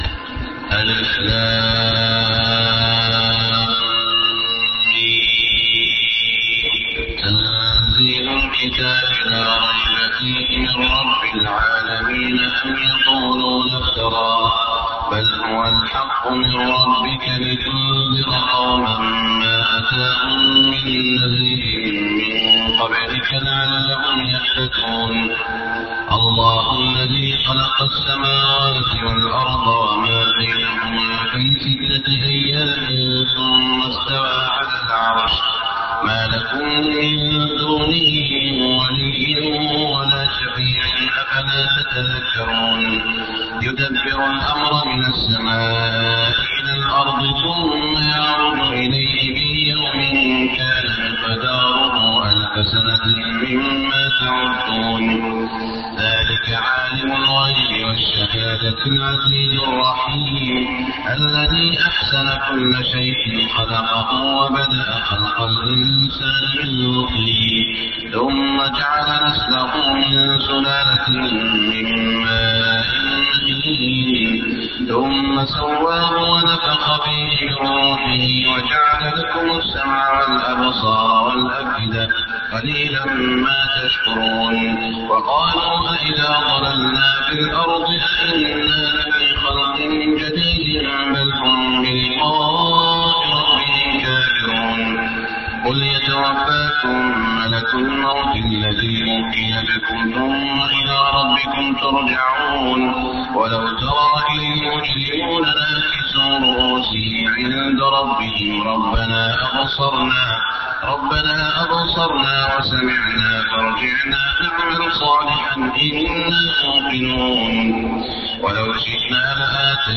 صلاة الفجر 1422هـ سورة السجدة > 1422 🕋 > الفروض - تلاوات الحرمين